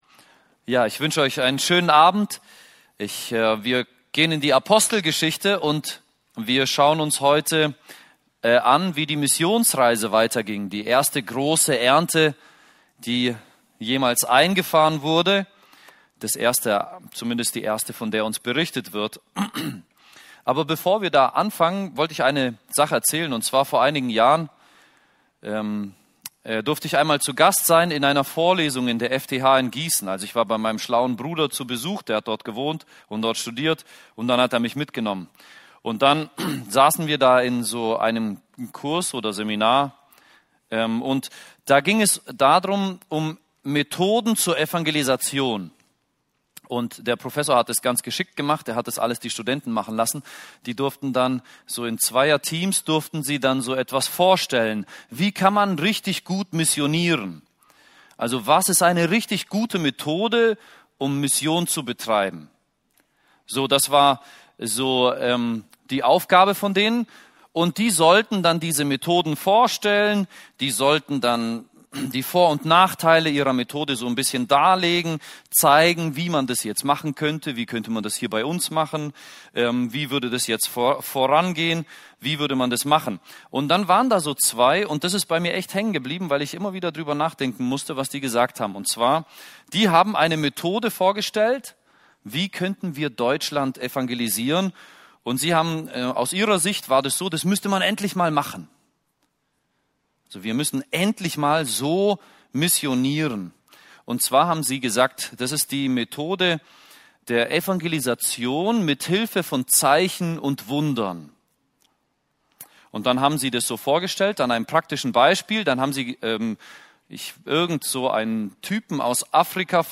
Hier findet ihr die Bibelstunden der Freikirchlichen Gemeinde Böbingen e.V.